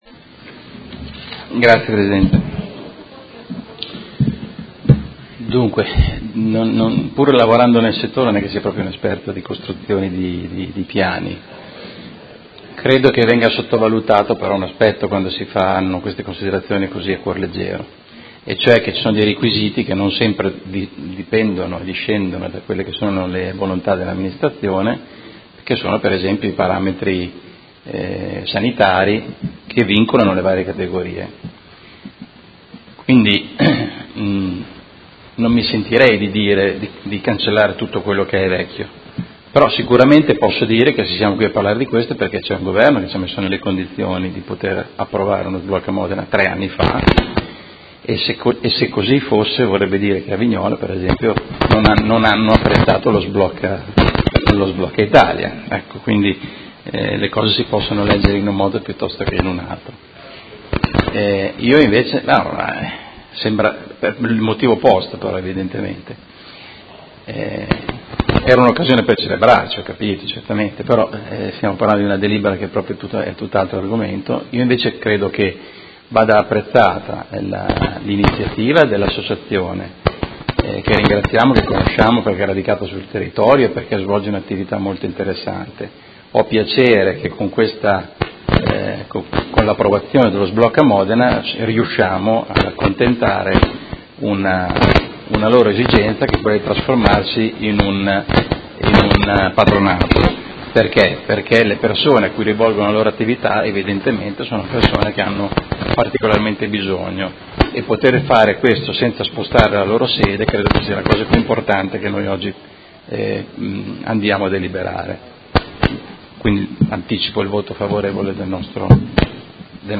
Seduta del 13/07/2017 Dibattito.